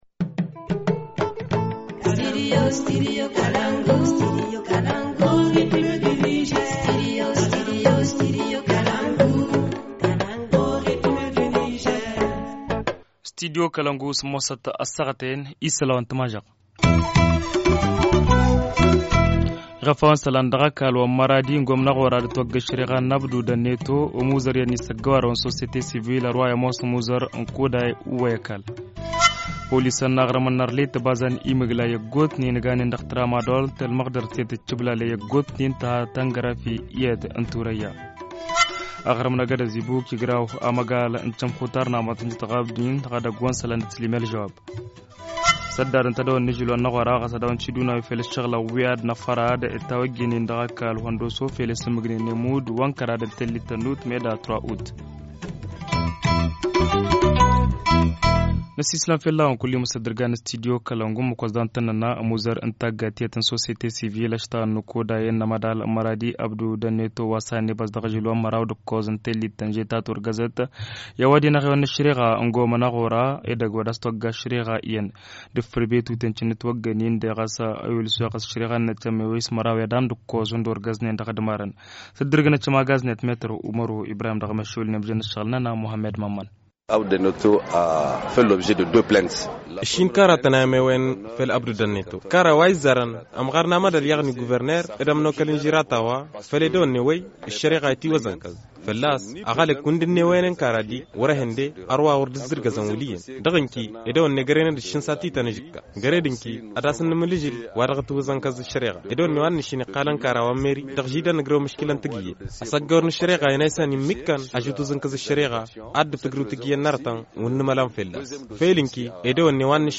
Journal du 27 juillet 2017 - Studio Kalangou - Au rythme du Niger